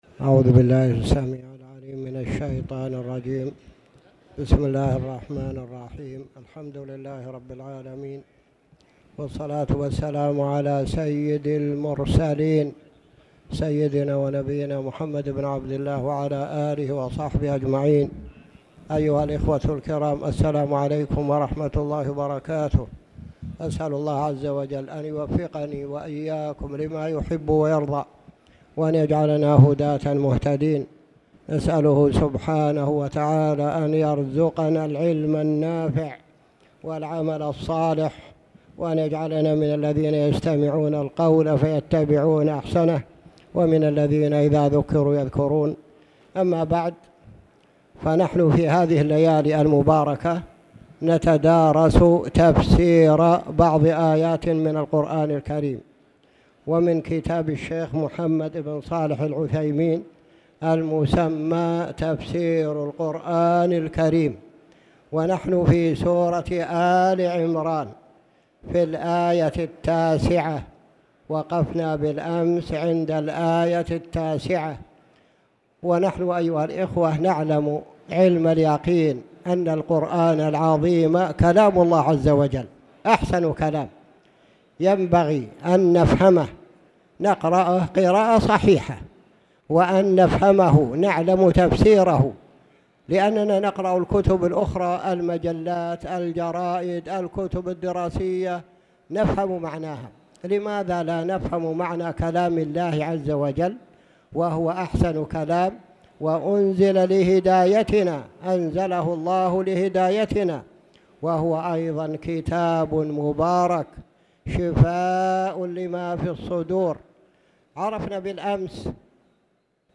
تاريخ النشر ١٠ ربيع الثاني ١٤٣٨ هـ المكان: المسجد الحرام الشيخ